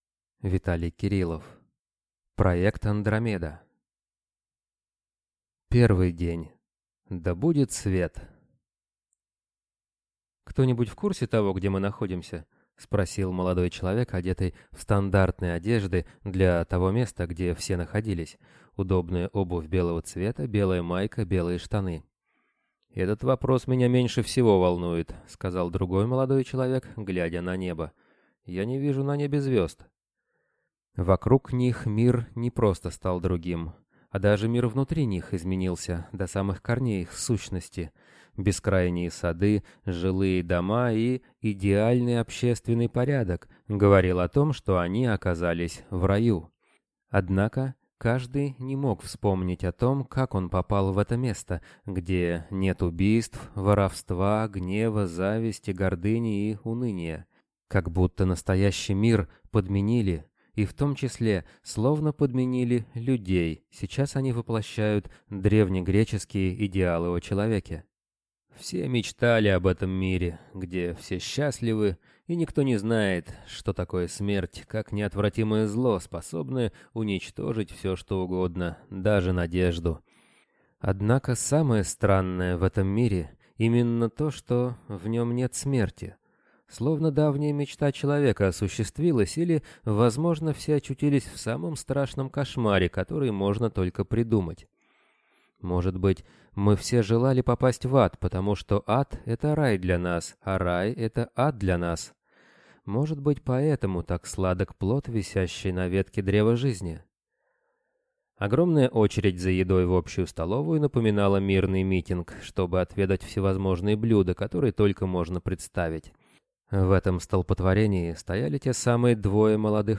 Аудиокнига Проект Андромеда | Библиотека аудиокниг
Прослушать и бесплатно скачать фрагмент аудиокниги